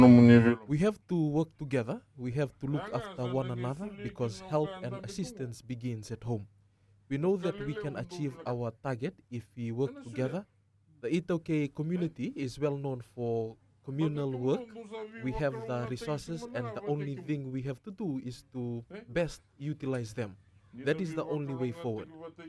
Minister for Agriculture and Waterways Vatimi Rayalu made the comment while speaking to the Nakoro/Nase Investment Cooperative in Vatukoula.